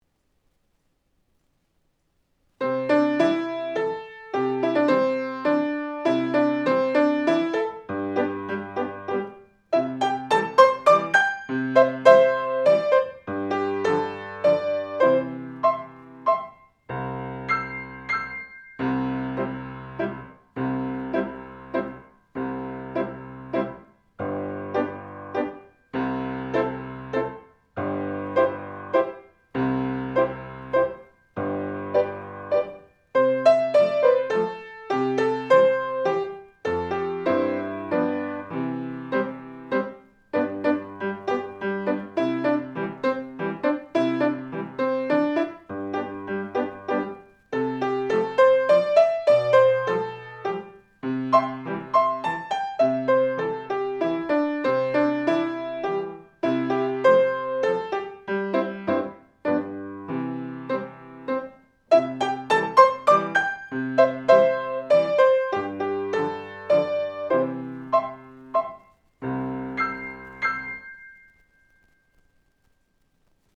⭐予選審査では、下記の課題曲の伴奏をご利用いただくことも可能です。
雨降りお月前奏８小節